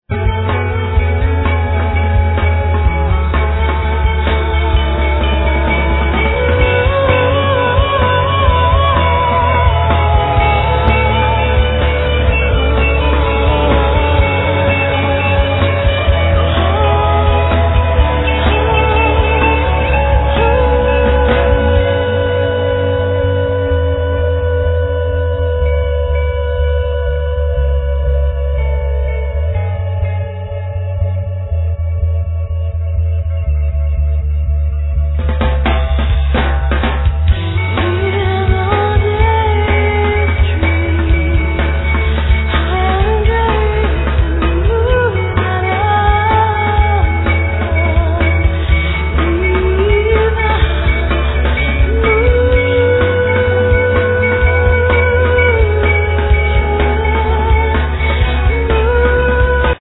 Guitar, Rhythm guitar, Guitar textures
Trapkit Drum set
Guitar, Cello, Loops, Samples
Bass, Piano
Doumbek, Riqq, Sitar, Tar
Vocals